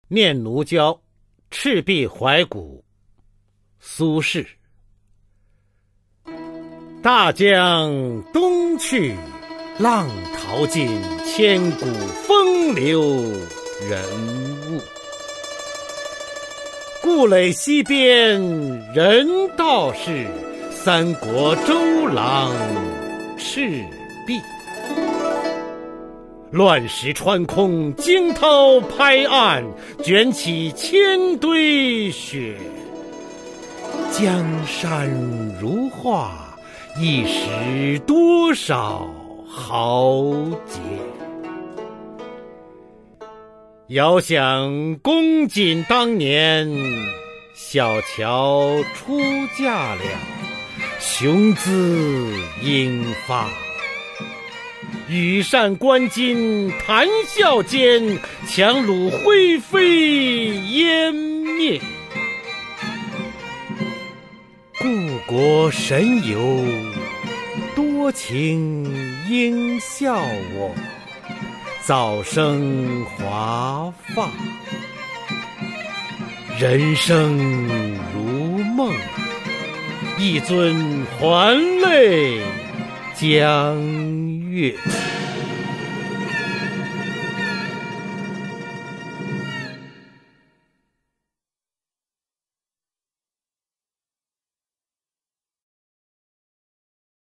[宋代诗词诵读]苏轼-念奴娇·赤壁怀古 宋词朗诵